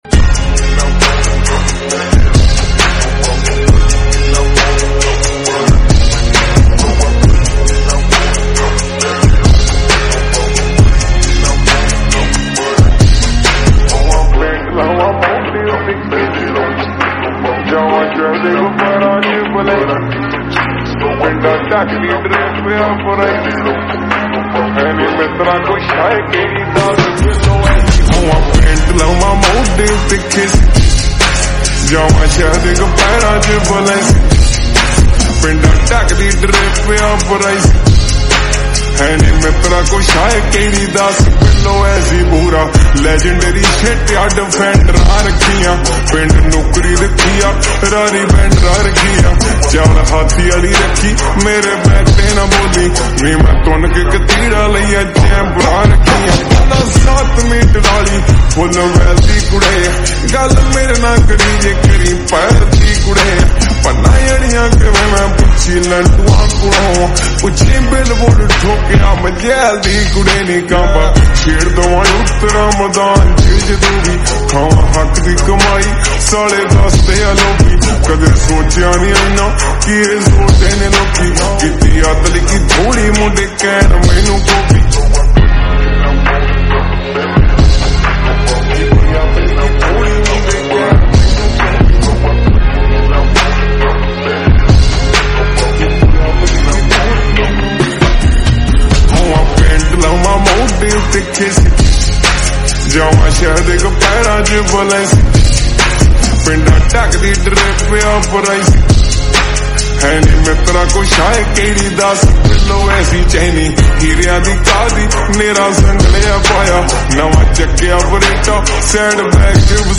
SLOWED AND REVERB